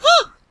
Sound Effect
I made these with a glass of water, a straw, some popsicle sticks, and my own voice.
scream.ogg